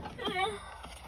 Memes
Funny Cut Scream